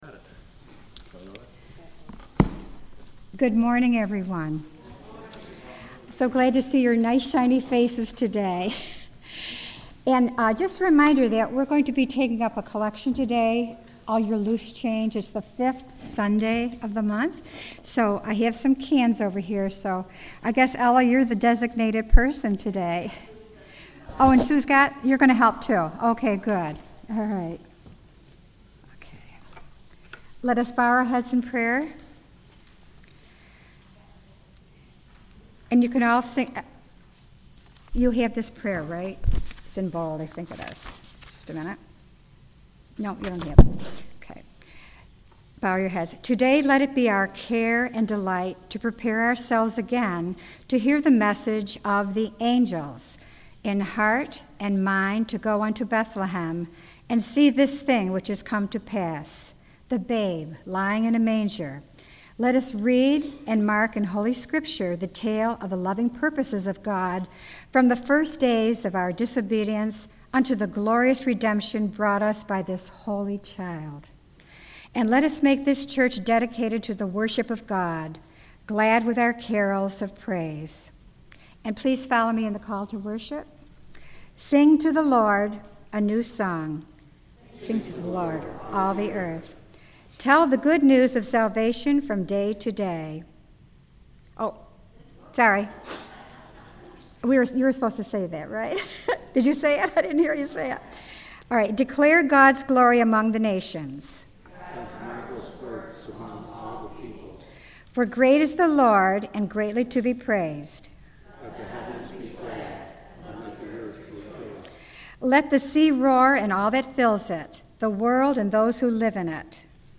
FPC Readings and Carols 18-12-30